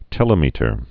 (tĕlə-mētər, tə-lĕmĭ-tər)